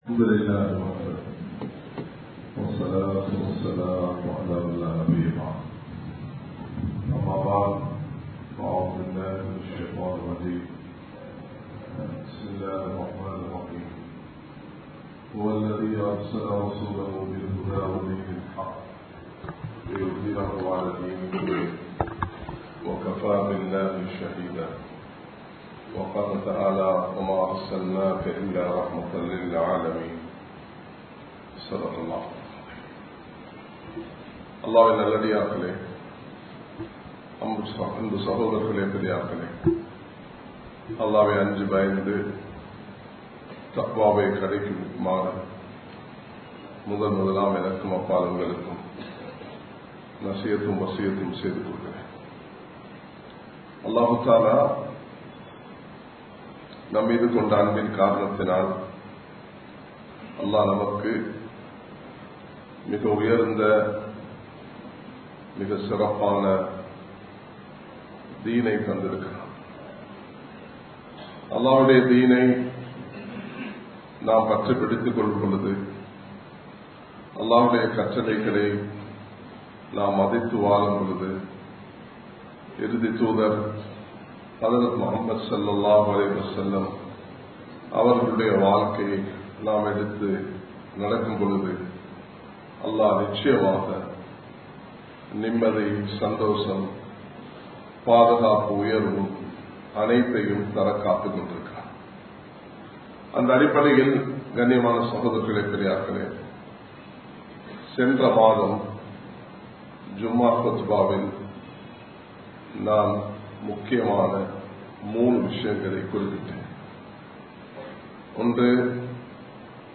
Colombo 11, Samman Kottu Jumua Masjith (Red Masjith)